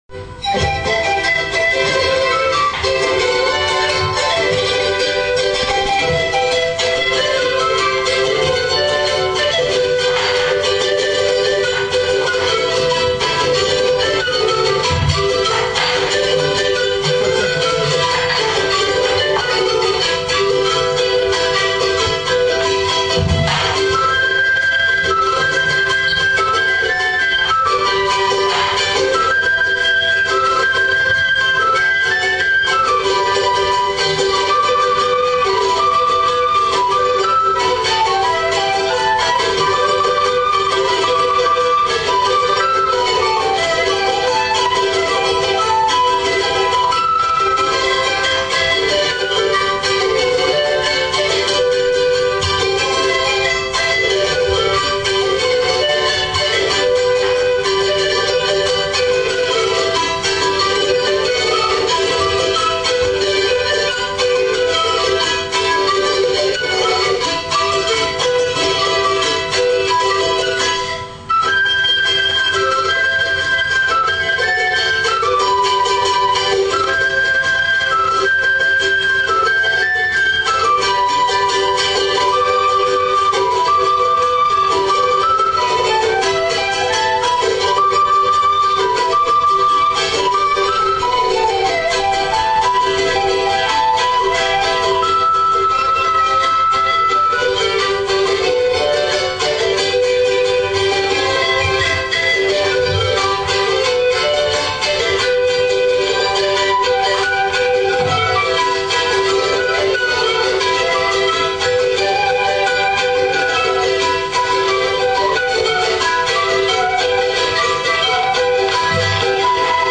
オカリナ曲名 ケーナ・サンポーニャ曲名